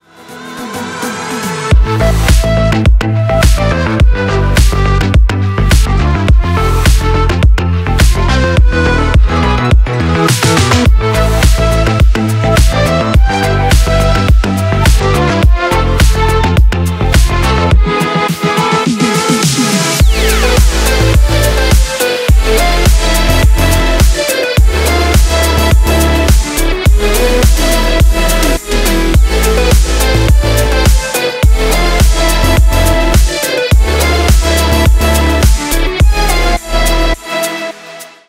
Электроника
клубные # без слов